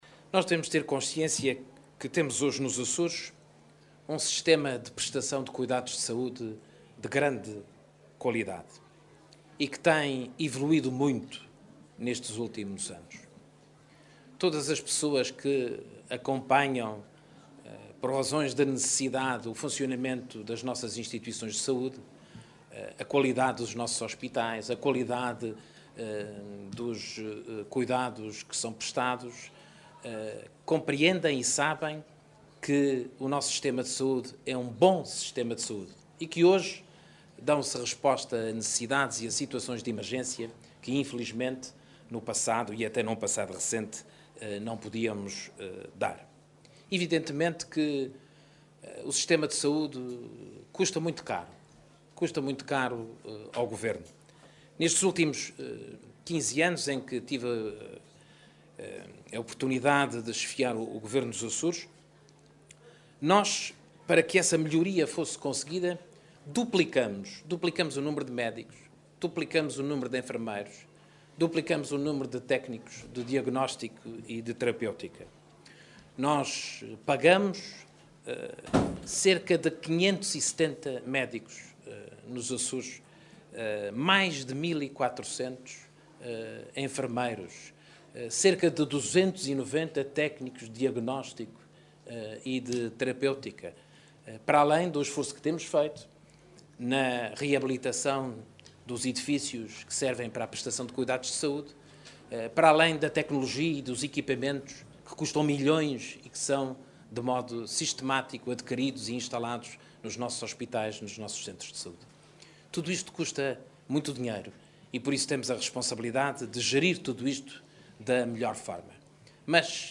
O Presidente do Governo Regional, que falava na cerimónia de inauguração do Posto de Saúde dos Remédios da Bretanha, acrescentou que “todas as pessoas que acompanham, por razões de necessidade, o funcionamento das nossas instituições de saúde – a qualidade dos nossos hospitais, a qualidade dos cuidados que são prestados – compreendem e sabem que o nosso sistema de saúde é bom.”